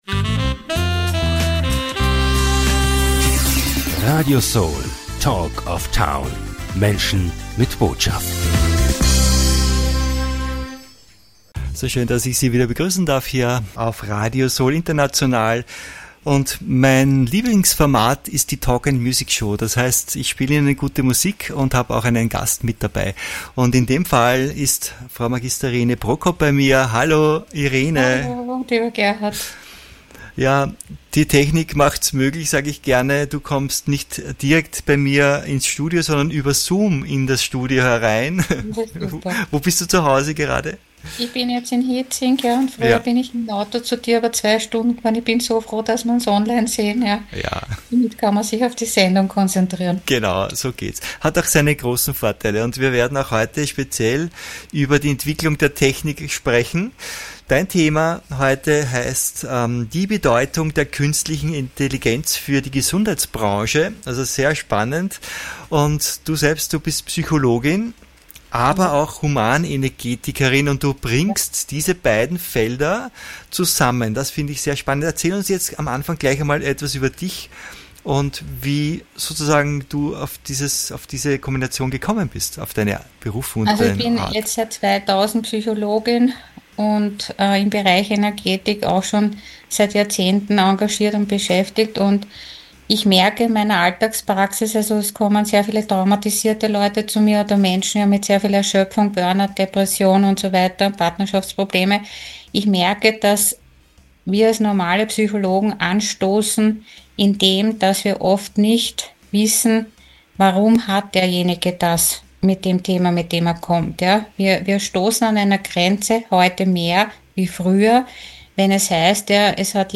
Sie verbindet diese beiden Berufungen auf wunderbare Weise und setzt dabei auch künstliche Intelligenz ein. Wie sie das macht, erfahren Sie in diesem spannenden Interview.